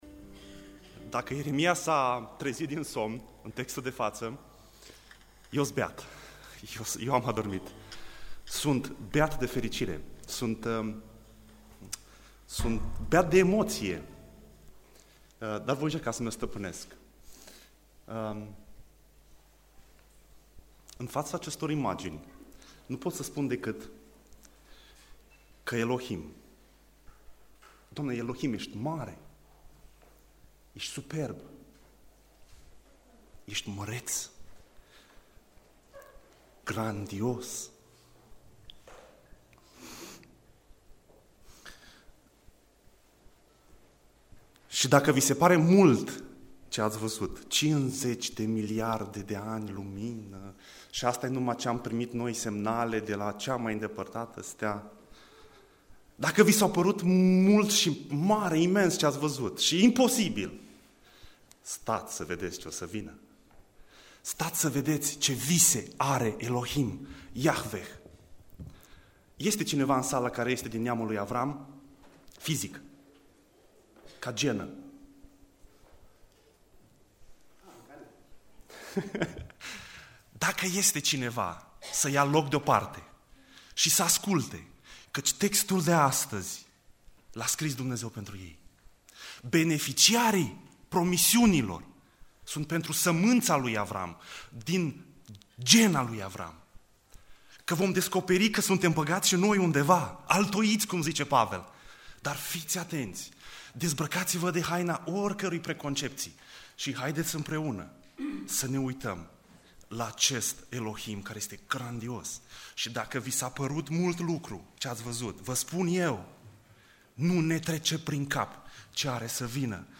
Predica Exegeza - Ieremia 31b